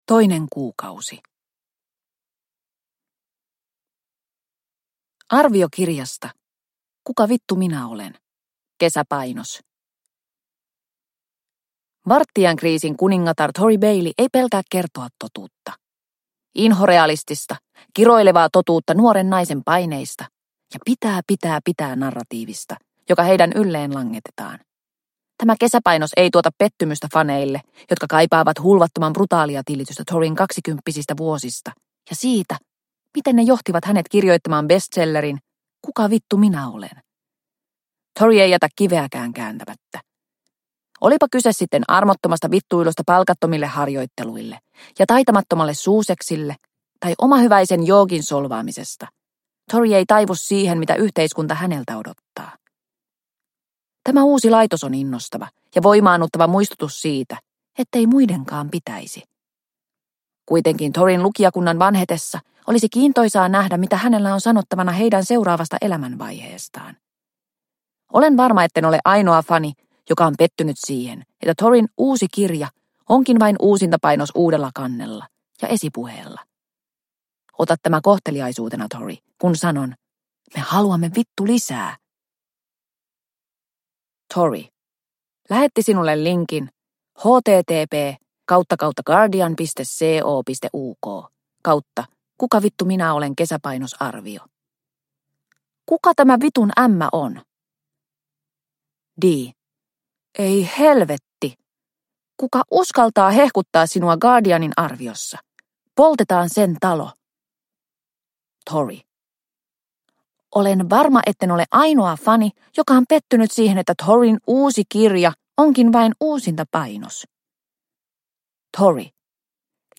Katsokaa, miten onnellinen olen – Ljudbok – Laddas ner